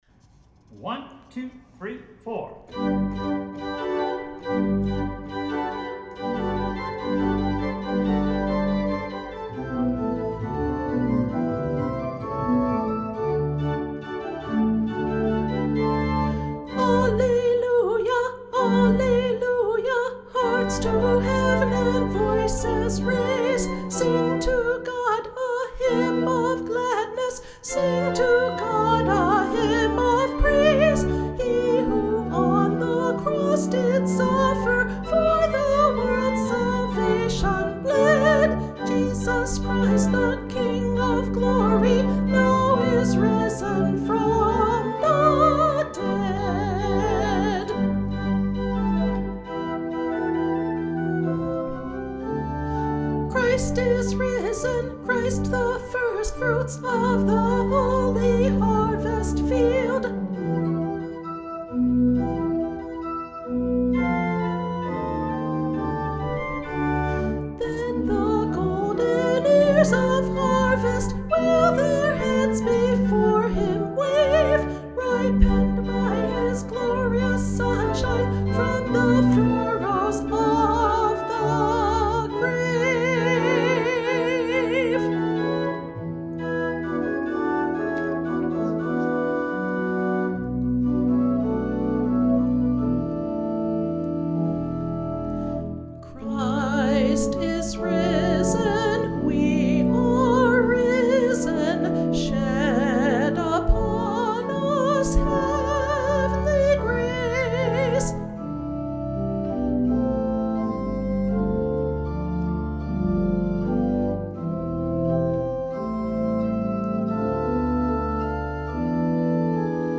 Soprano Track Alto Track Tenor Track Bass Track
Alleluia-Christ-is-Risen-Soprano-Guide.mp3